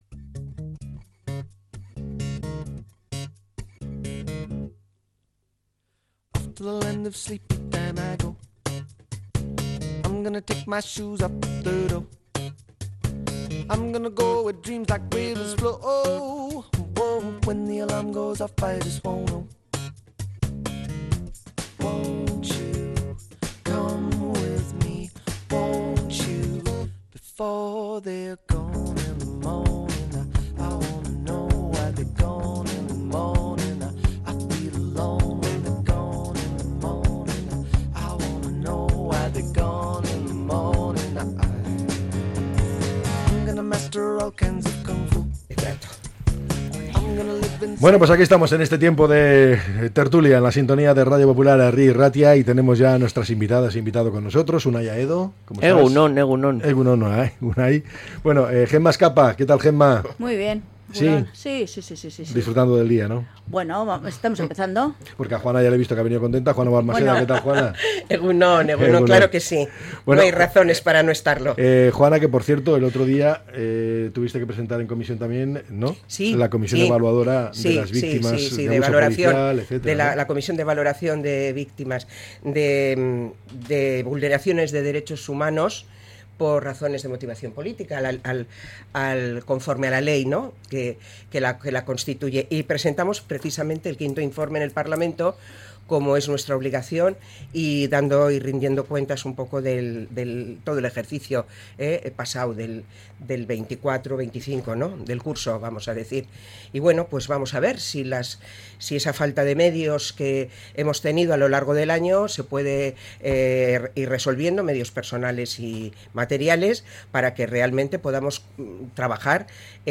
La tertulia 16-10-25.